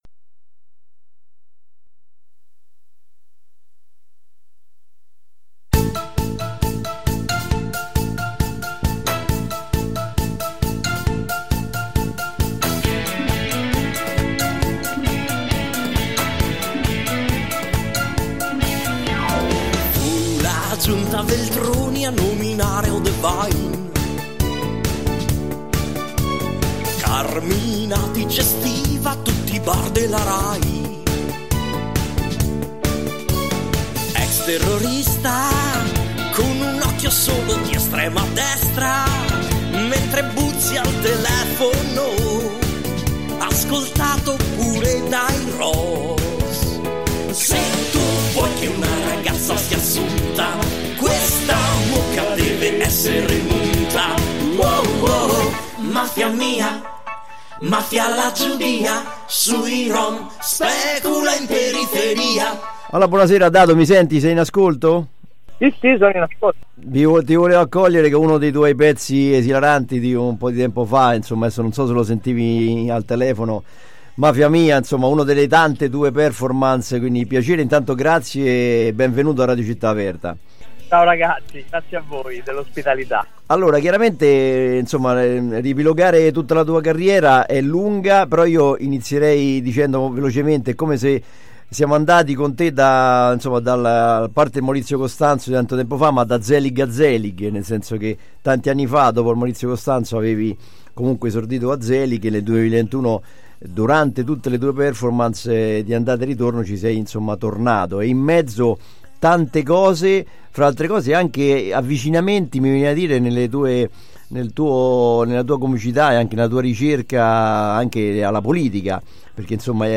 Dado a teatro | Intervista